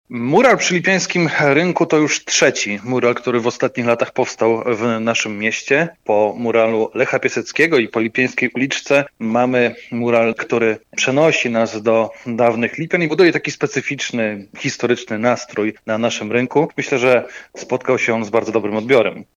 „Mural przy Lipiańskim rynku, to już trzeci mural, który w ostatnich latach powstał w naszym mieście. Po muralu Lecha Piaseckiego i po Lipiańskiej uliczce mamy mural, który przenosi nas do dawnych Lipian i buduje taki specyficzny historyczny nastrój na naszym rynku. Myślę, że spotkał on się z bardzo dobrym odbiorem” – dla Twojego radia mówił Burmistrz Lipian Bartłomiej Królikowski, który zaznacza, że przy jego powstawaniu zamysł był taki, by zilustrować nieistniejące już budynki w centrum miasta.